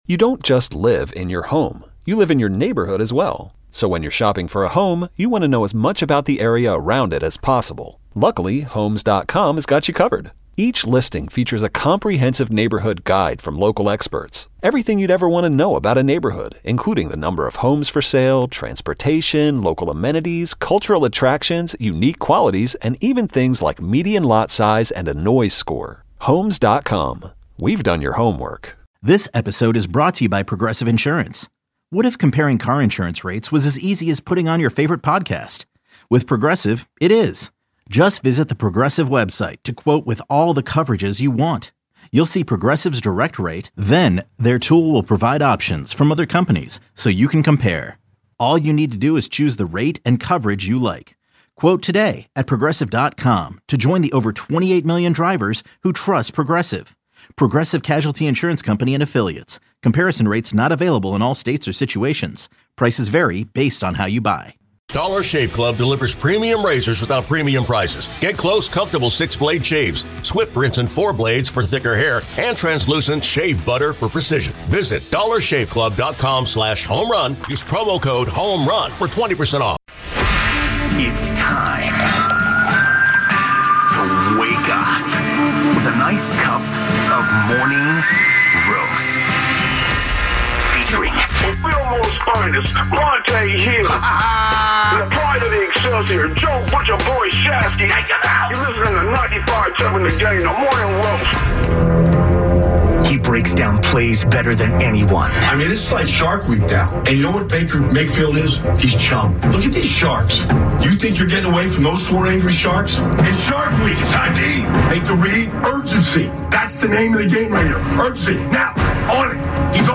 San Francisco 49ers tight end George Kittle called into Bay Area radio station 95.7 The Game on Thursday morning and, as the four-time All-Pro often tends to do, covered an abundance of topics ranging from sneakers to Netflix's 'Receiver' series to what he's been seeing from the defense in practice.